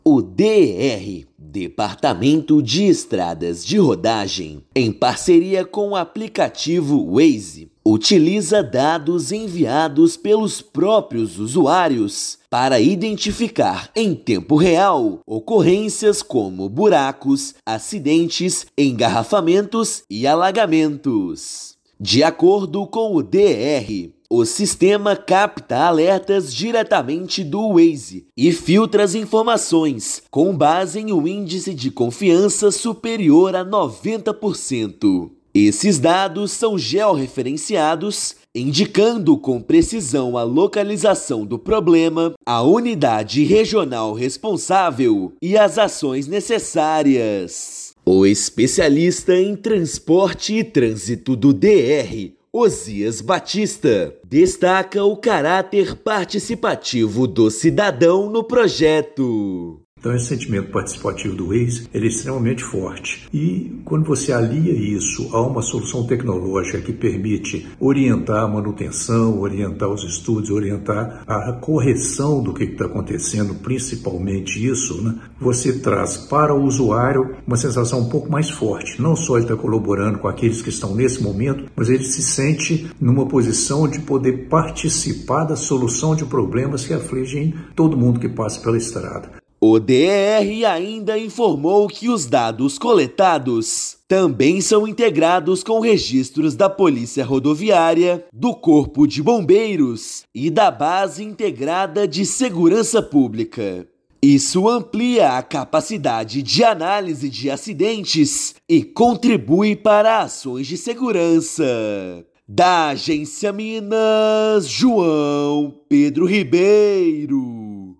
Tecnologia de ponta coloca usuários no centro da gestão rodoviária no estado. Ouça matéria de rádio.